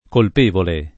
colpevole [ kolp % vole ]